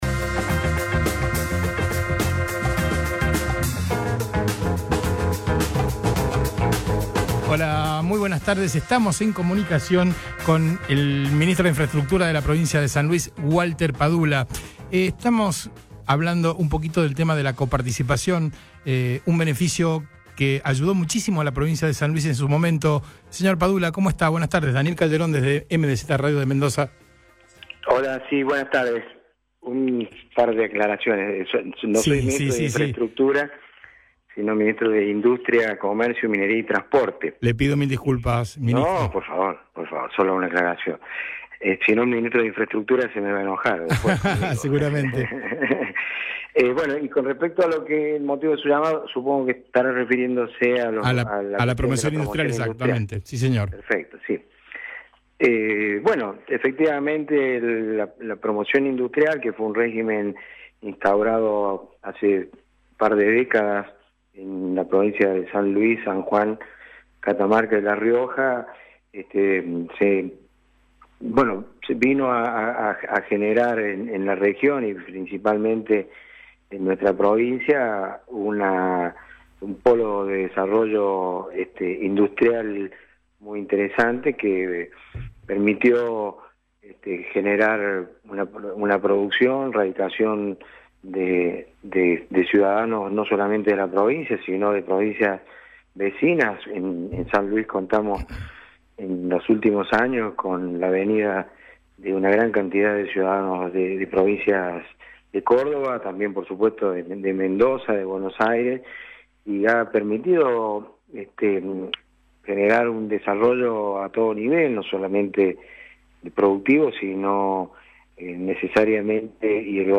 Si bien en principio era para anunciar la creación del Consejo Olivícola Federal, la conferencia derivó en consultas sobre las opiniones de los funcionarios en cuanto a la caída de la Promoción.